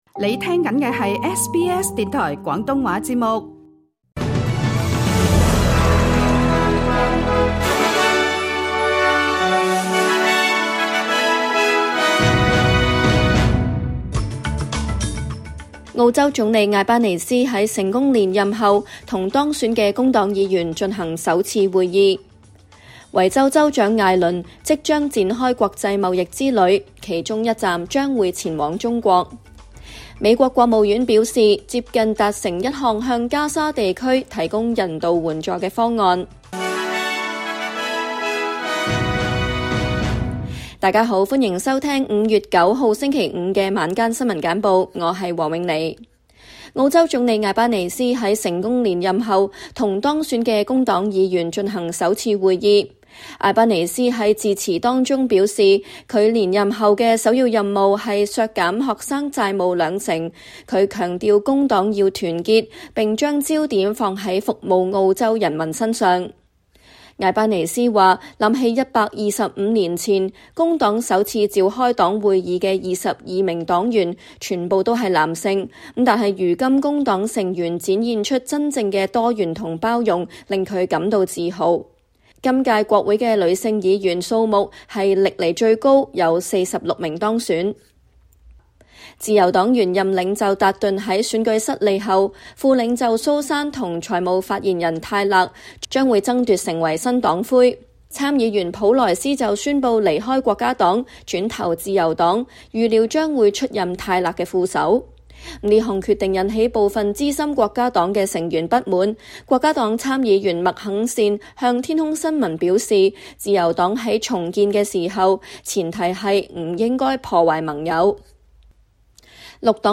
SBS 廣東話晚間新聞